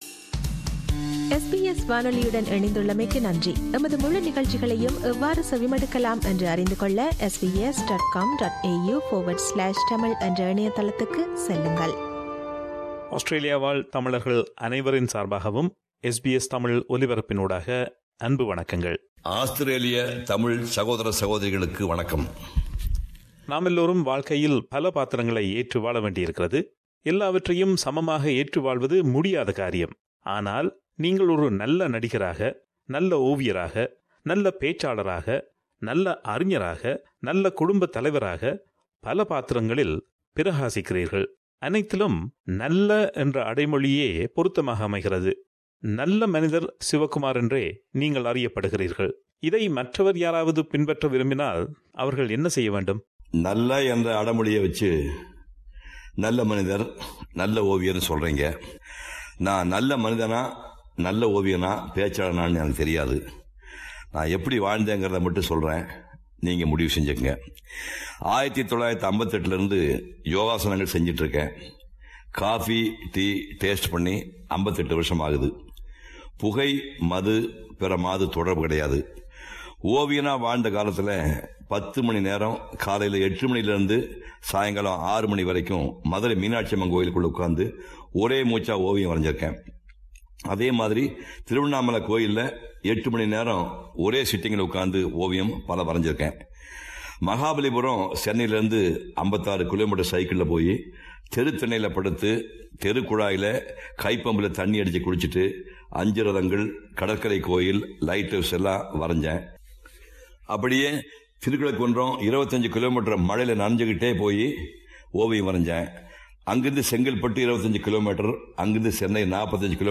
Painter, Actor, and Orator Sivakumar is a multi-facet artist. In this first of the three-part interview